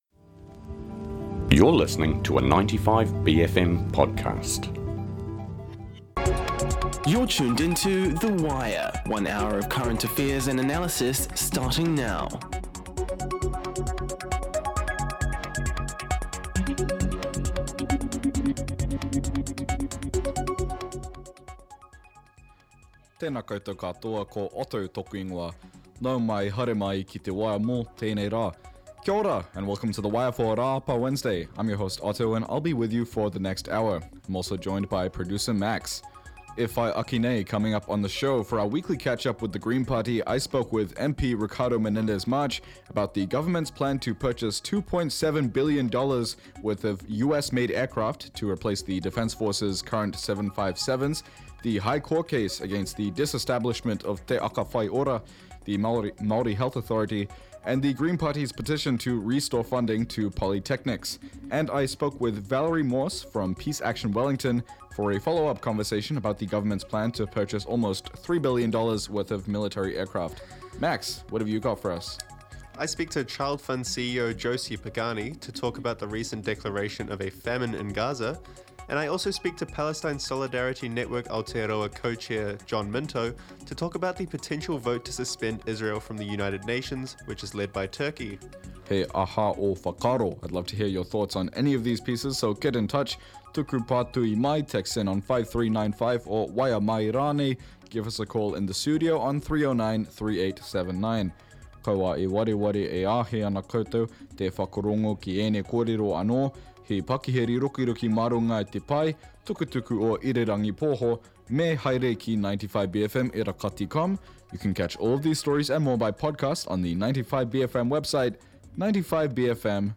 The Monday Wire includes our weekly catch-ups with the ACT Party's Simon Court and Te Pāti Māori's Takutai Kemp. The Wire is 95bFM's long-running daily bastion of news, current affairs and views through the bFM lens.